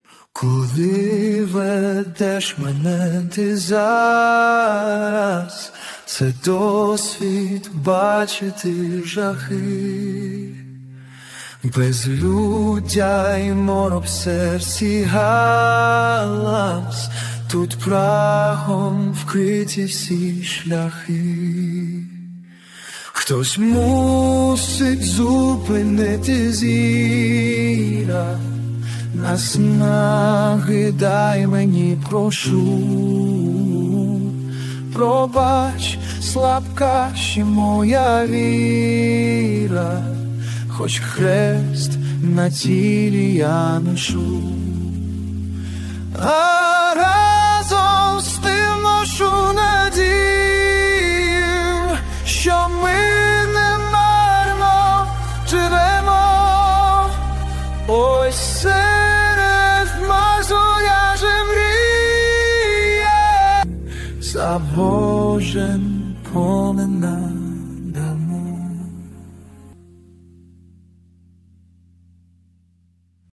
Текст - автора, виконання пісні - ШІ
ТИП: Пісня
СТИЛЬОВІ ЖАНРИ: Ліричний